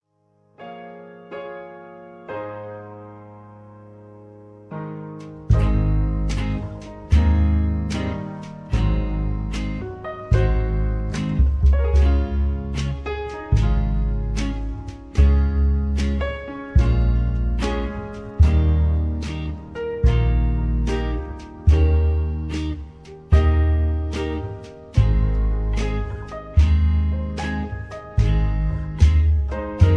karaoke, mp3 backing tracks
rock, r and b, rock & roll